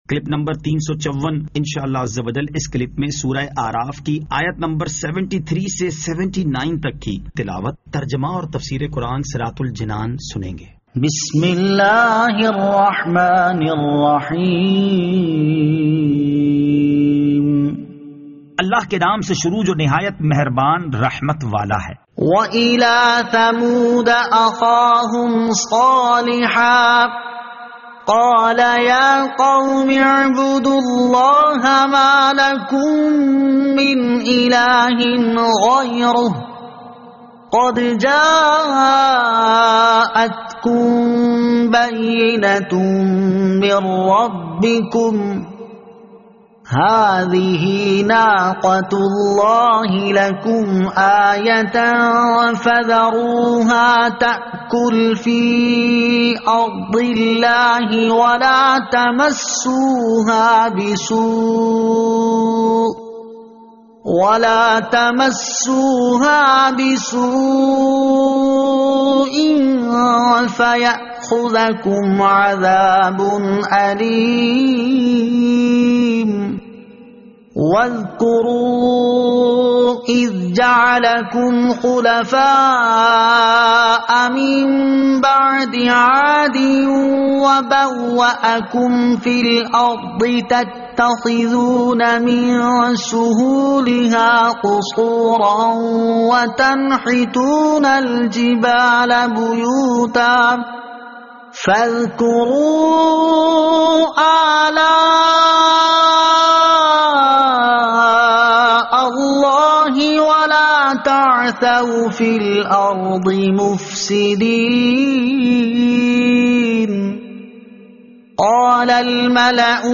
Surah Al-A'raf Ayat 73 To 79 Tilawat , Tarjama , Tafseer